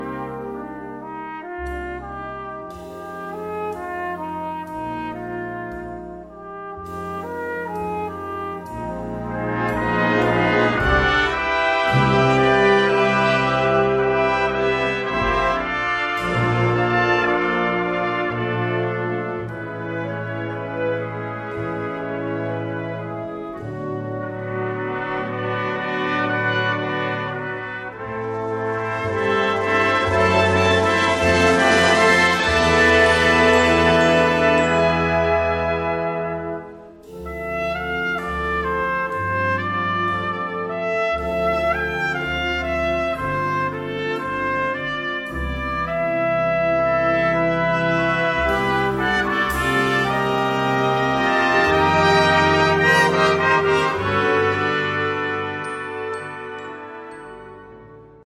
5 trompettes et Orchestre d'Harmonie (11'26)
* Studio DAVOUT.